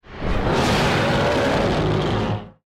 File:Giant mutant widow queen spider roar.mp3
Giant_mutant_widow_queen_spider_roar.mp3